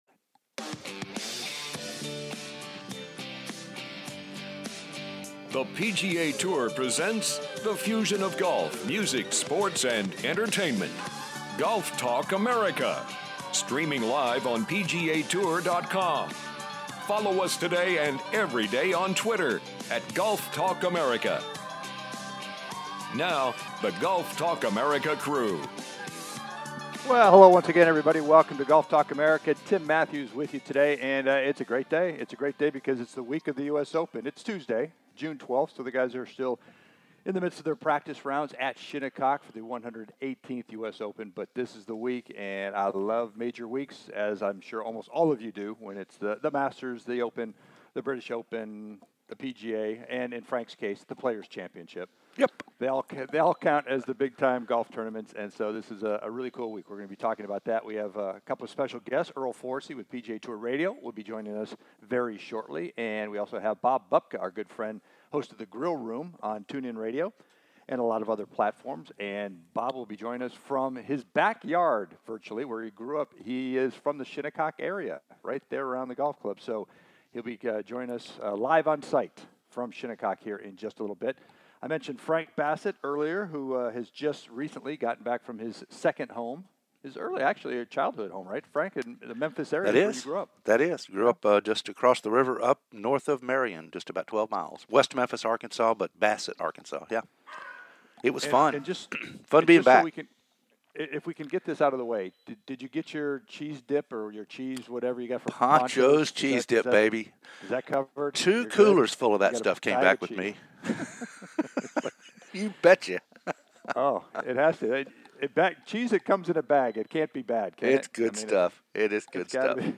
"LIVE" The From The US OPEN